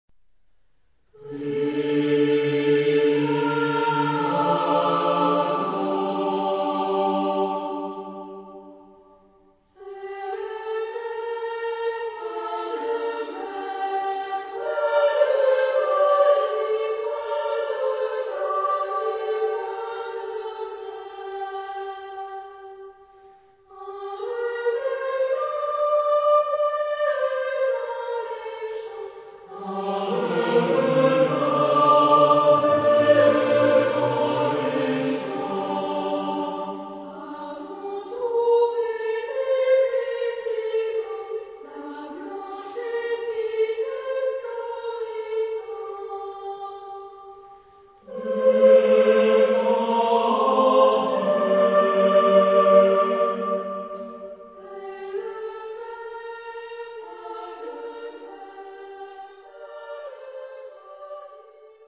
Type de choeur : SATB  (4 voix mixtes )